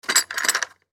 На этой странице собраны реалистичные звуки капканов разных типов: от резкого металлического щелчка до глухого захлопывания.
Звук капкана растянулся